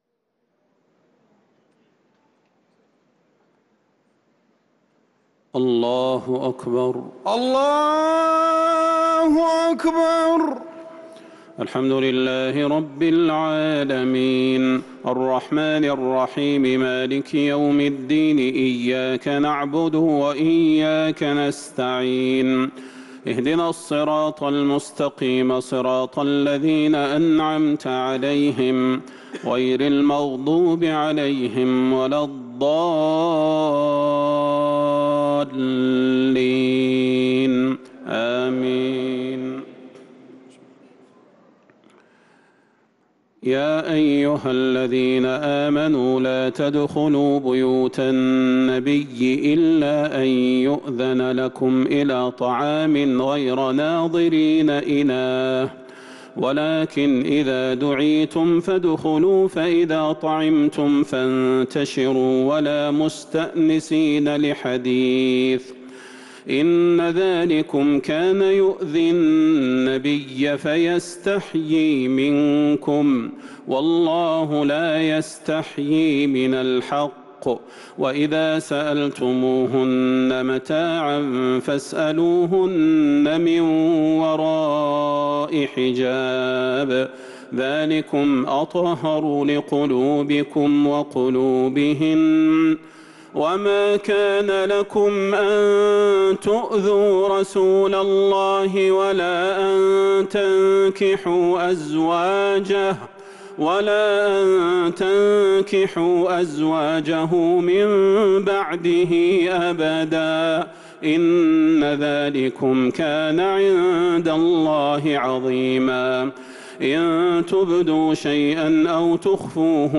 صلاة التراويح ليلة 24 رمضان 1443 للقارئ صلاح البدير - التسليمتان الاخيرتان صلاة التهجد